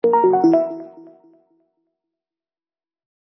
lose.wav